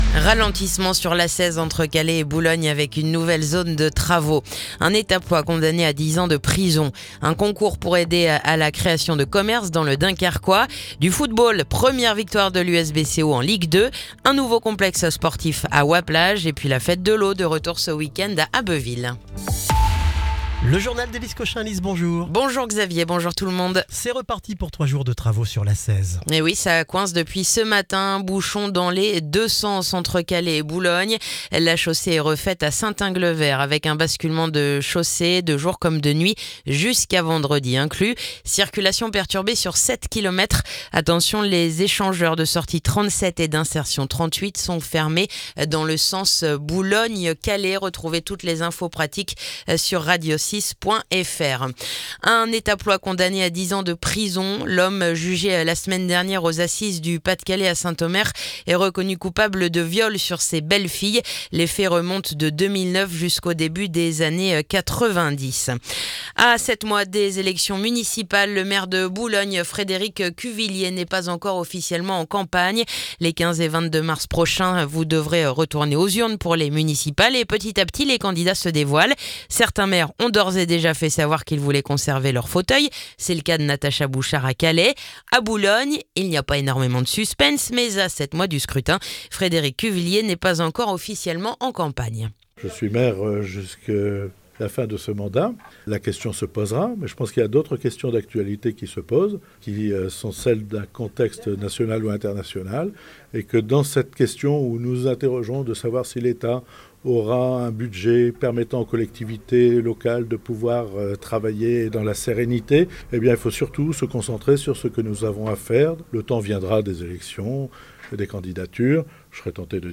Le journal du mercredi 17 septembre